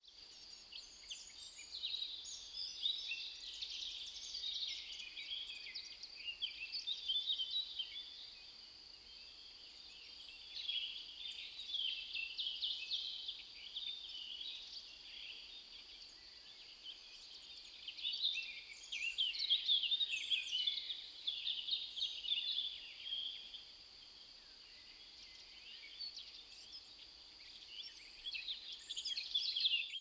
forest_mono.wav